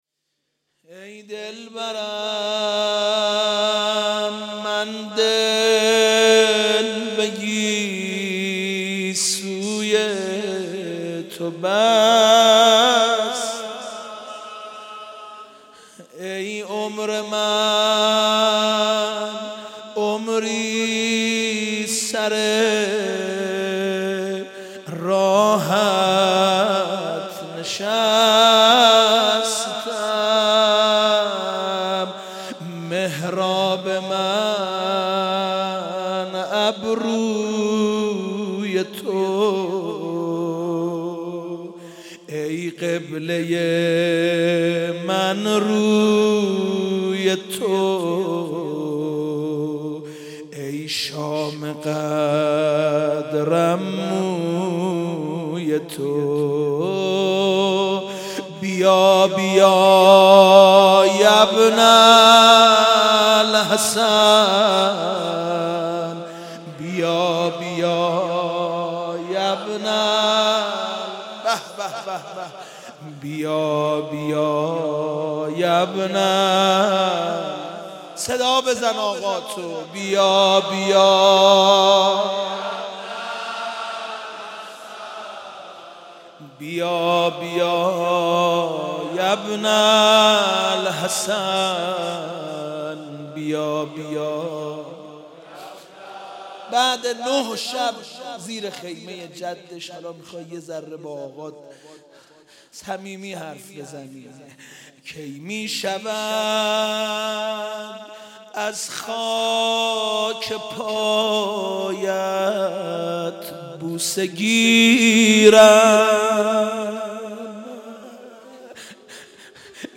مداحی
مراسم شب تاسوعا محرم ۱۳۹۲